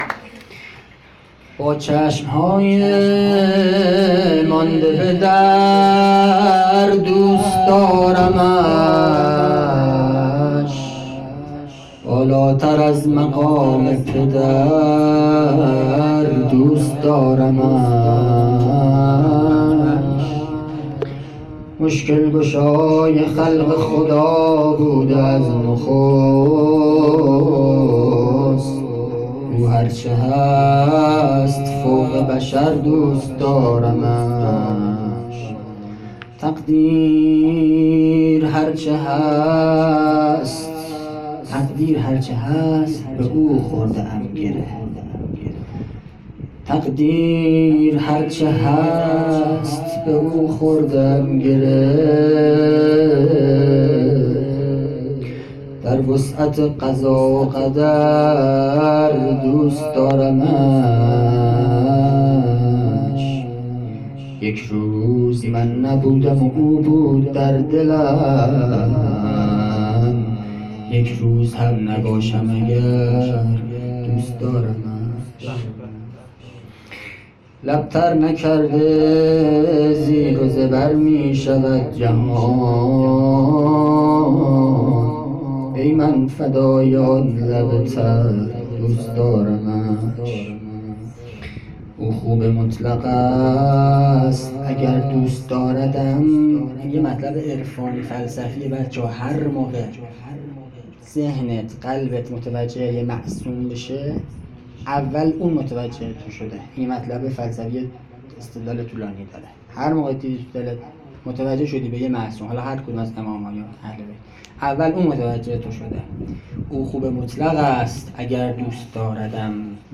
شعر پایانی
شهادت امیرالمؤمنین امام علی(ع)